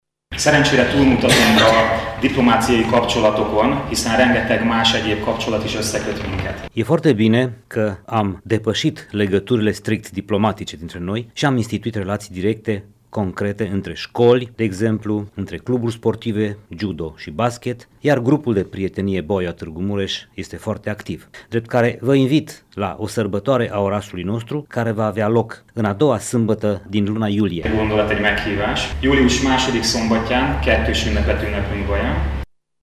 Întâlnirea a avut loc în sala de protocol a Primăriei.